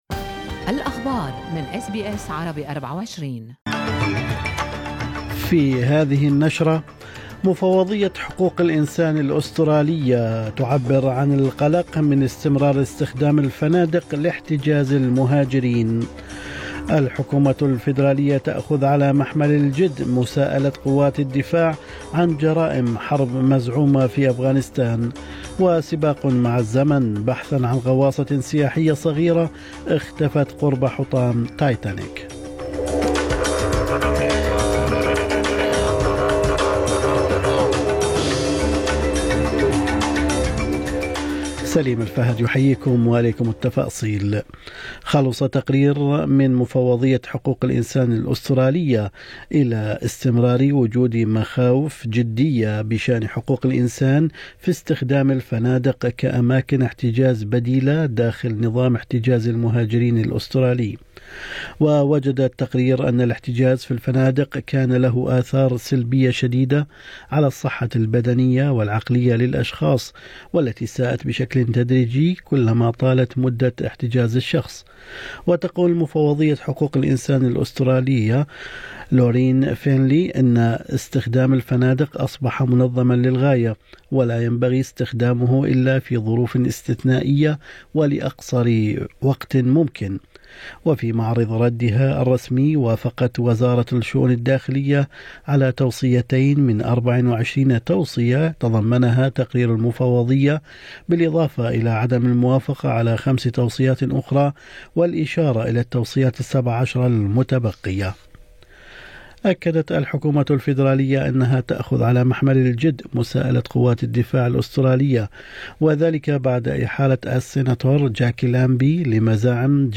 نشرة اخبار الصباح 21/6/2023